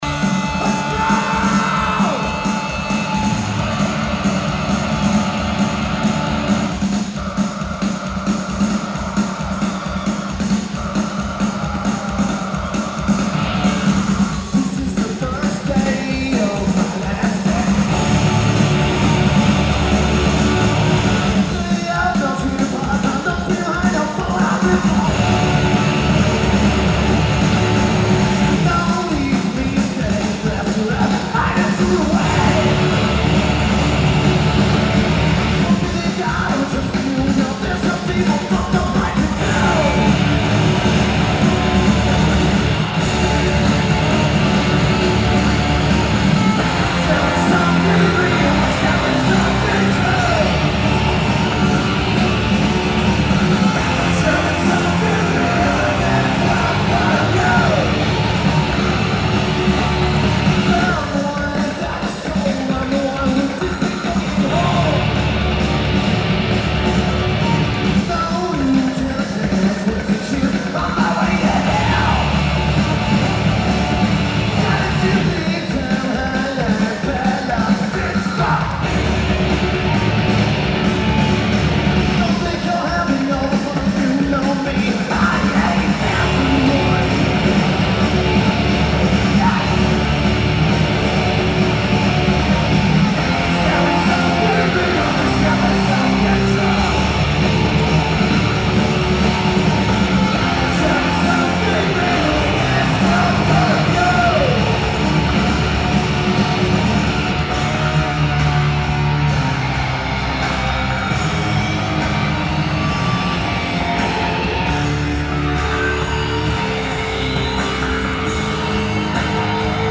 Theatre Du Forum
Drums
Bass/Guitar
Vocals/Guitar/Keyboards